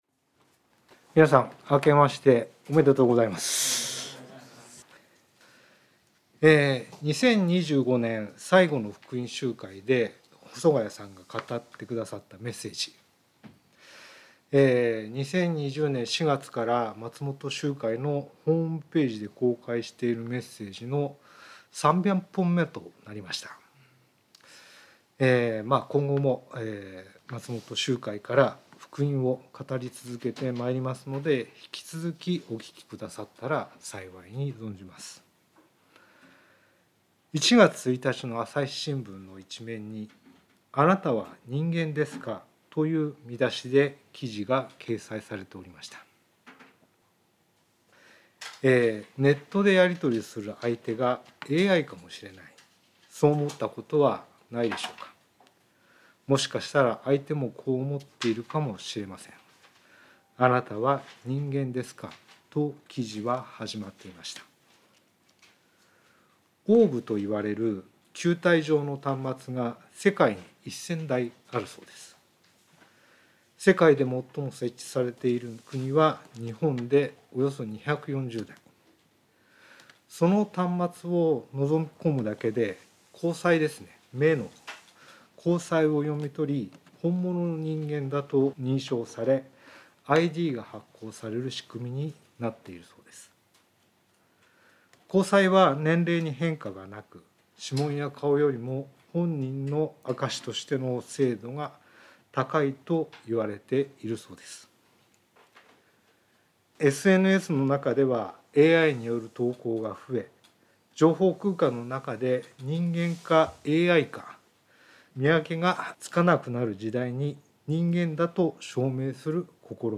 聖書メッセージ No.301